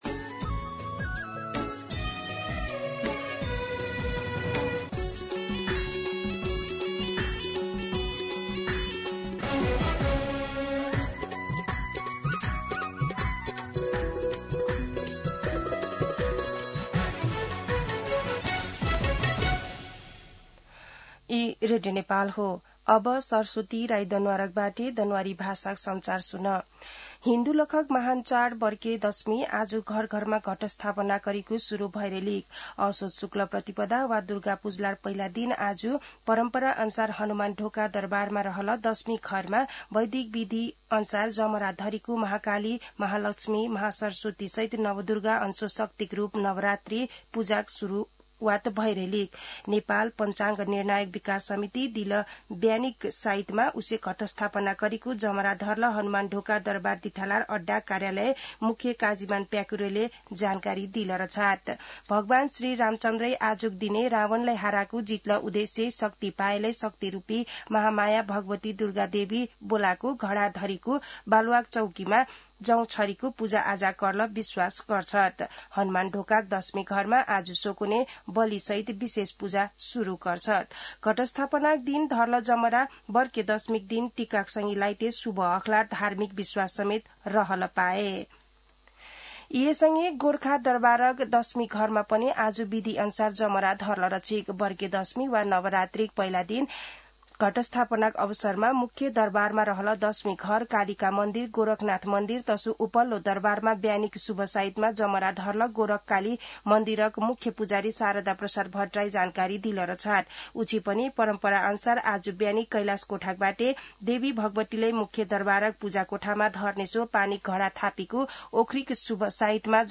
दनुवार भाषामा समाचार : ६ असोज , २०८२
Danuwar-News-7.mp3